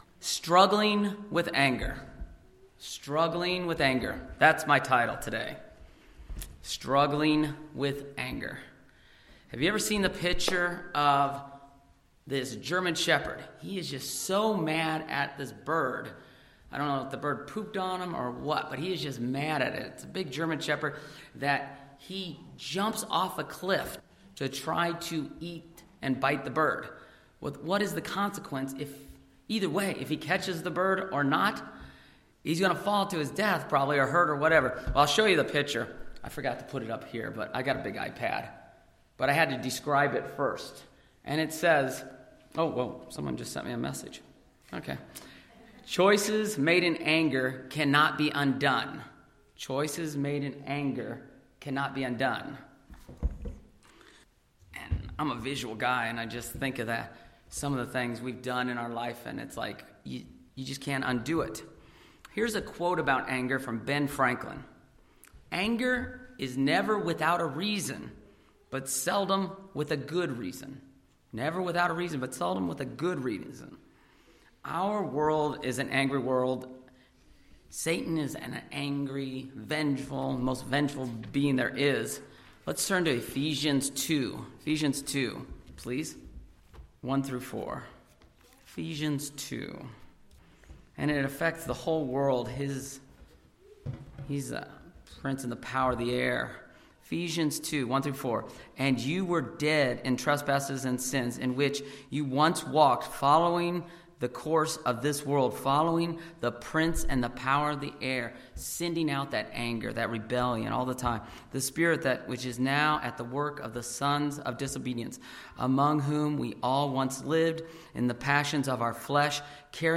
This sermon looks at a couple of examples from the bible of how to handle anger properly and improperly.
Given in Ft. Wayne, IN